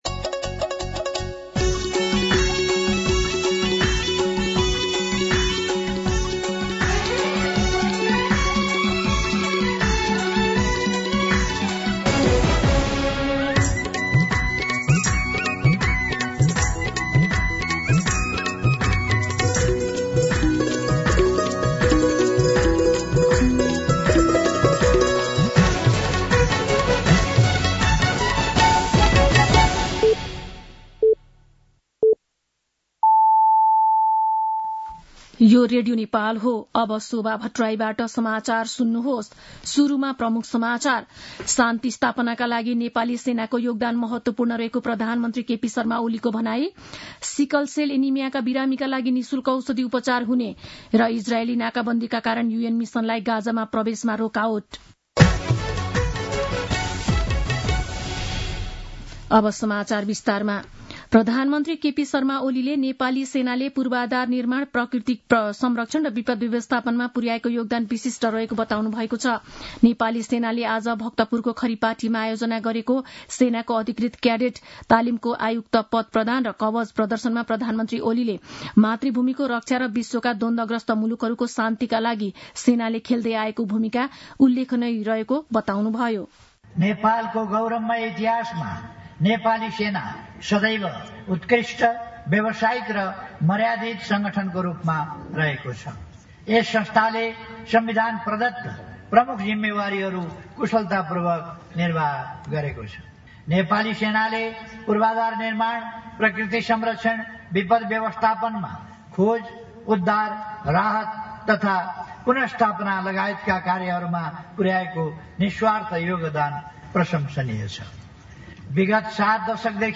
दिउँसो ३ बजेको नेपाली समाचार : २९ चैत , २०८१
3-pm-Nepali-News-2.mp3